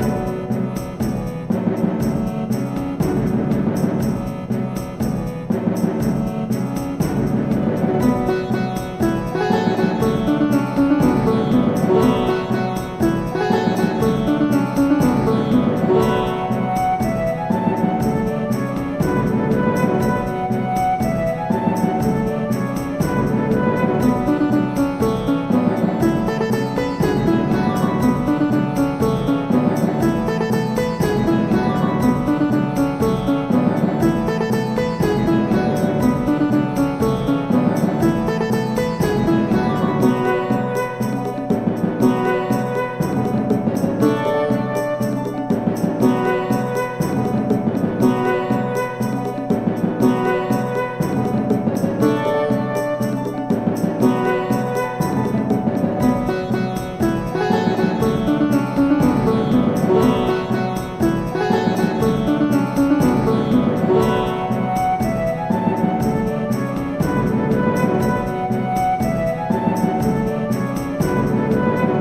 Une musique composée en juillet 2009, l'époque où j'écrivais encore des triolets :